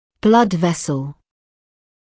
[blʌd ‘vesl][блад ‘вэсл]кровеносный сосуд